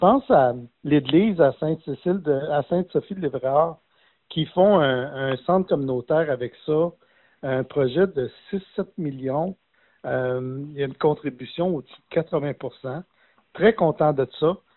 Le député a également mentionné une autre annonce pour la région.